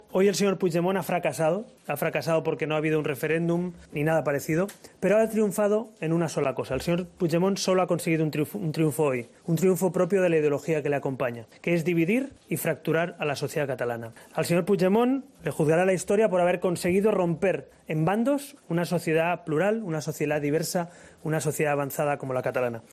"Lo de hoy sólo ha sido una coartada, una excusa para declarar unilateralmente la independencia. El programa electoral de JxSí y la CUP es romper Catalunya por la mitad, separarla de España y sacarnos de Europa", ha dicho Rivera en rueda de prensa en Barcelona tras el cierre de los colegios electorales.